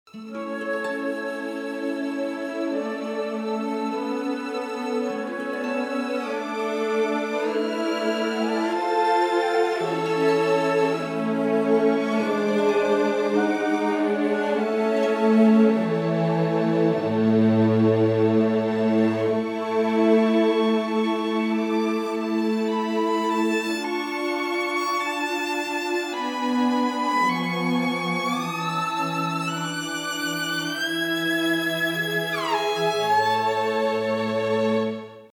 Category: Classical